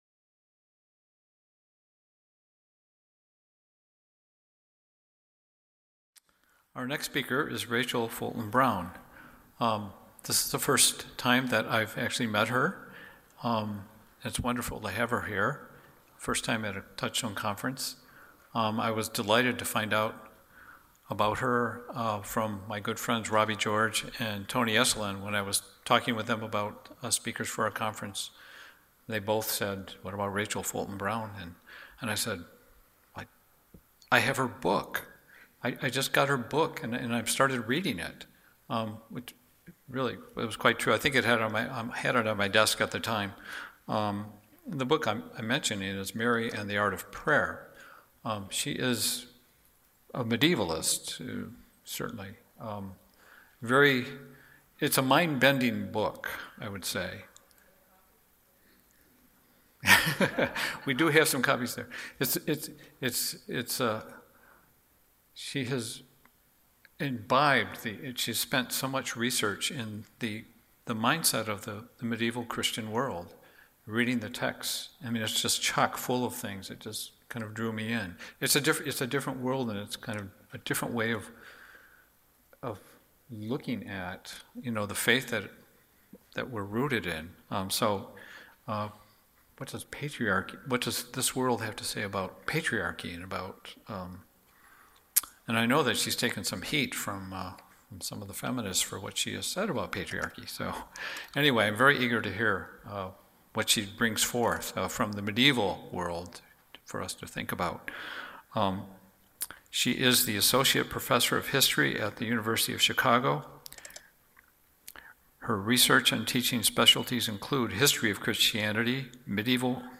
Duration: 43:52 with discussion afterwards — Talk delivered on Thursday, October 11, 2018